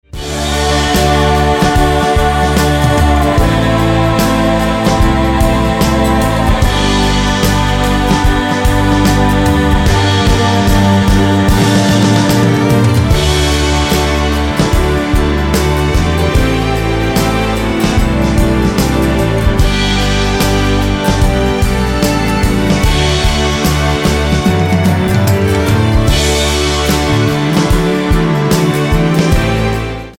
--> MP3 Demo abspielen...
Tonart:D mit Chor